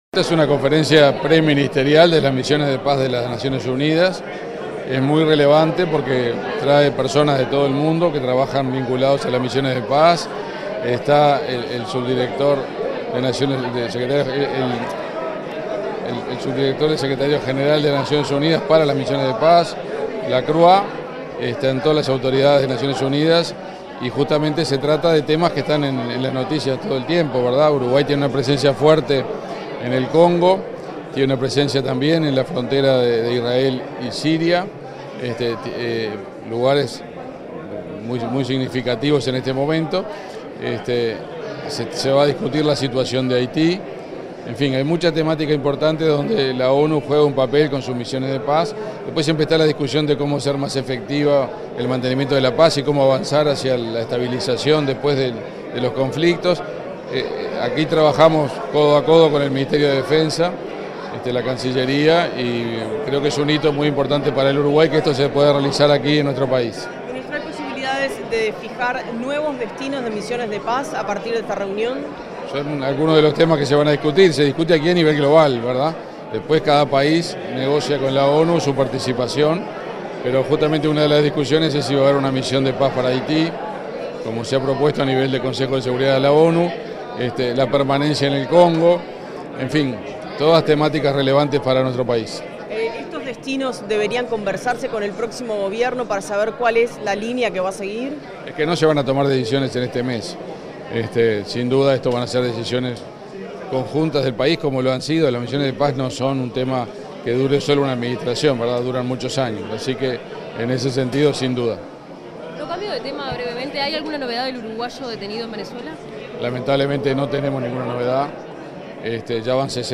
Declaraciones del ministro de Relaciones Exteriores, Omar Paganini
Declaraciones del ministro de Relaciones Exteriores, Omar Paganini 10/12/2024 Compartir Facebook X Copiar enlace WhatsApp LinkedIn Tras la Reunión Preparatoria Ministerial de las Naciones Unidas sobre Mantenimiento de la Paz, este 10 de diciembre, el ministro de Relaciones Exteriores, Omar Paganini, dialogó con los medios informativos presentes.